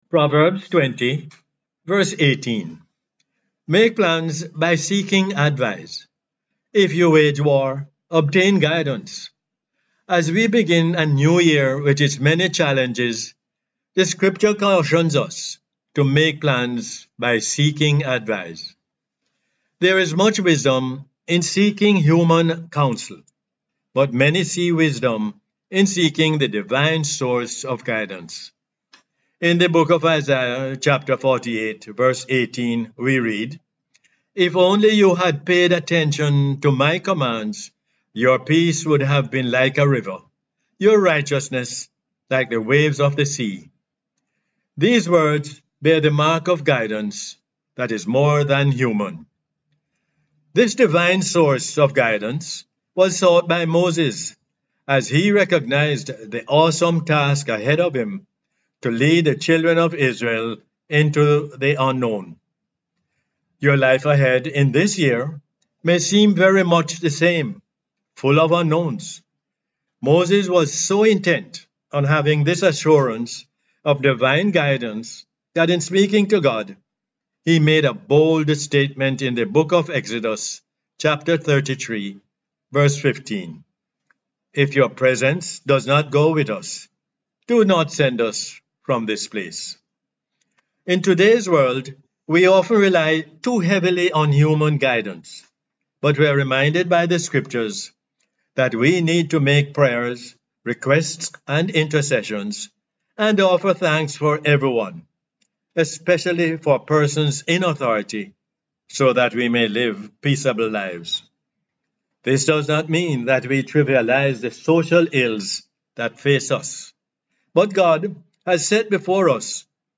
Proverbs 20:18 is the “Word For Jamaica” as aired on the radio on 20 January 2023.